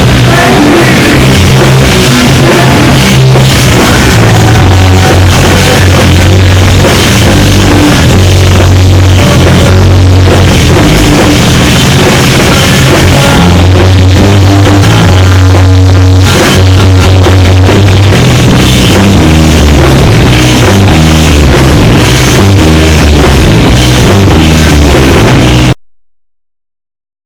Leeds show